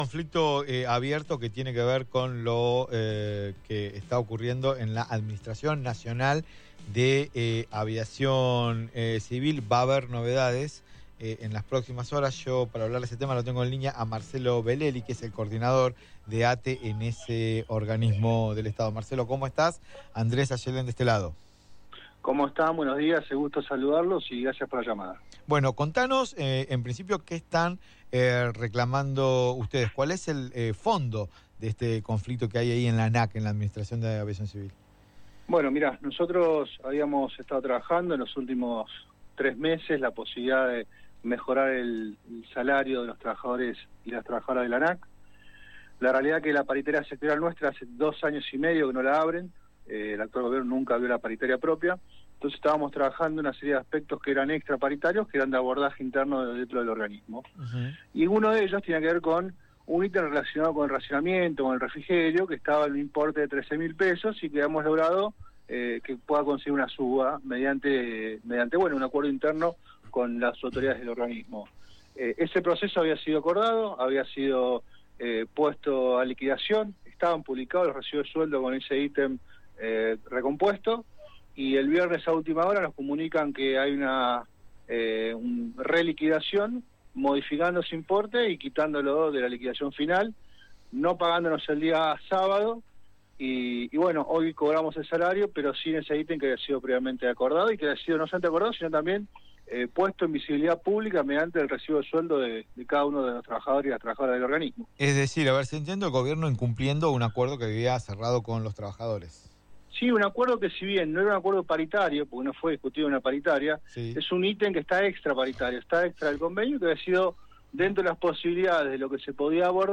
En comunicación con Es un Montón, al aire de Radio Provincia AM1270, aseguró que los trabajadores de la entidad llevan a cabo la asamblea desde las 11.00 hs en el Aeropuerto Internacional de Ezeiza.